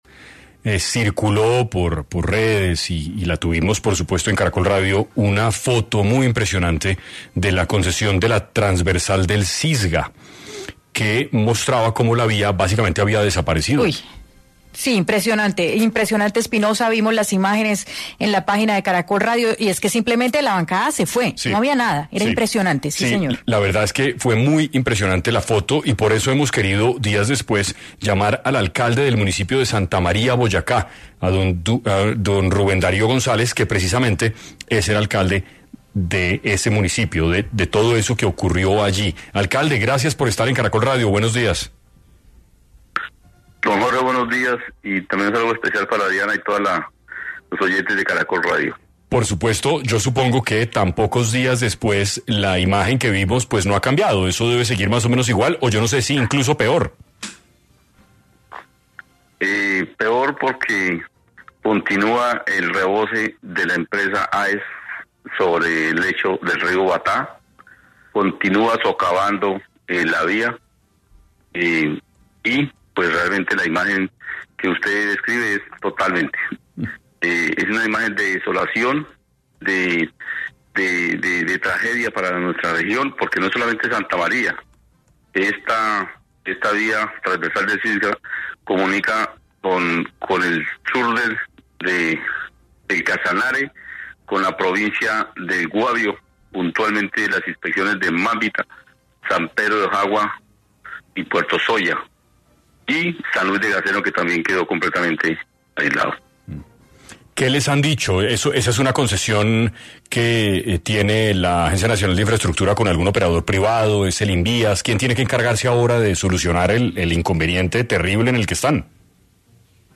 En 6AM de Caracol Radio estuvo Rubén Darío González, alcalde del municipio de Santa María Boyacá, quien explicó cómo se encuentra la situación en el lugar tras emergencias causadas por lluvias.